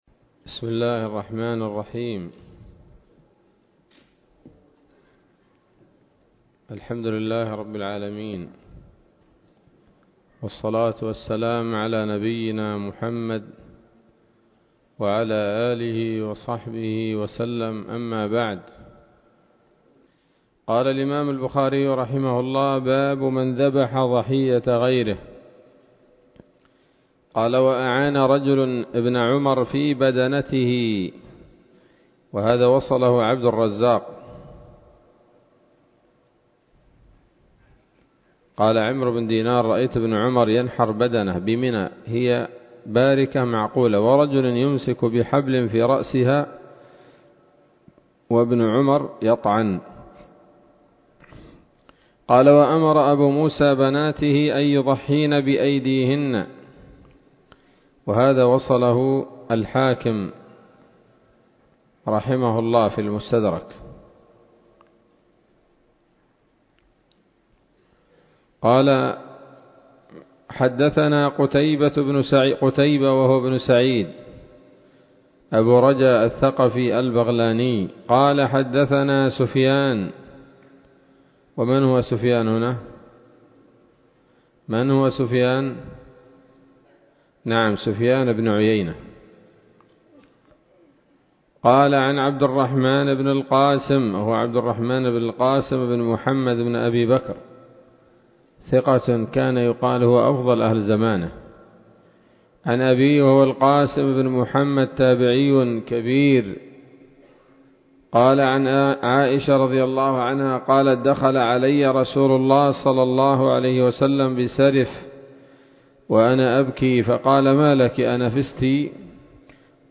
الدرس العاشر من كتاب الأضاحي من صحيح الإمام البخاري